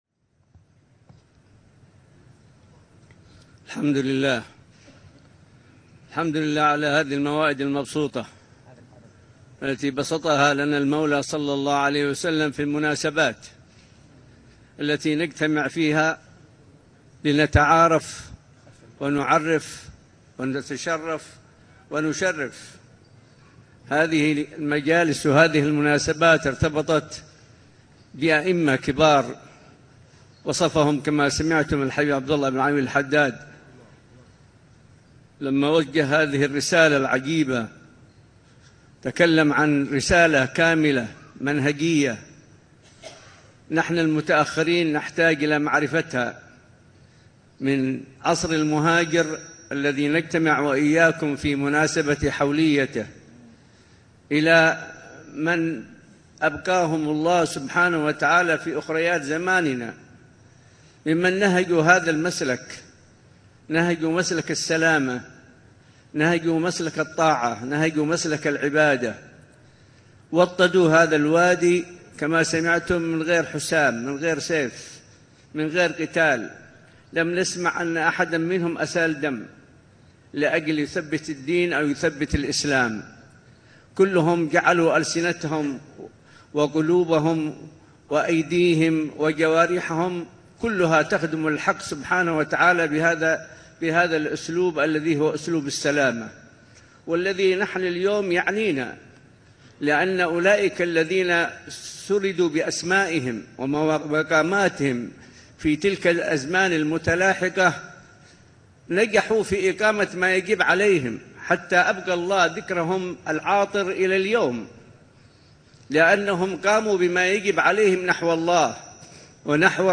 محاضرة
مساء الأربعاء ١٤ محرم ١٤٤٢ه‍ بمسجد الأحمدين – شعب الإمام المهاجر – الحسيسة – حضرموت.